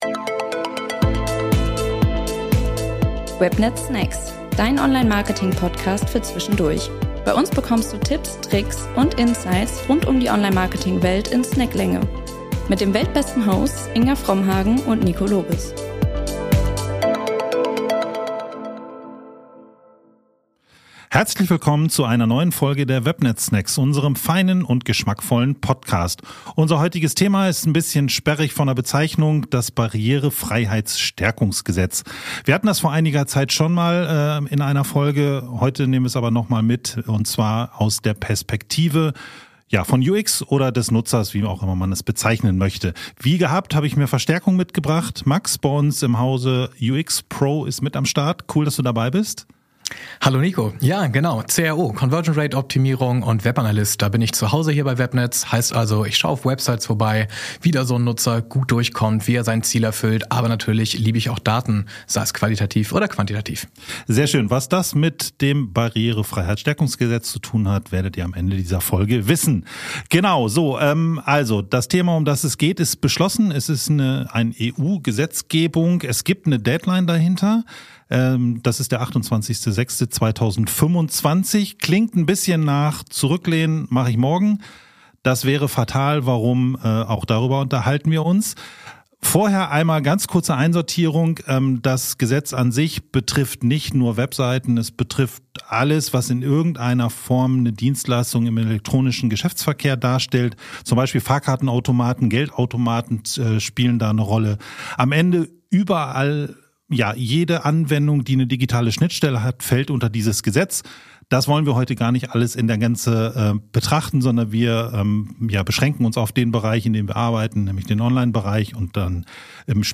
Im Gespräch erörtern die beiden die Bedeutung und Auswirkungen dieses Gesetzes auf Websites und digitale Angebote von Unternehmen.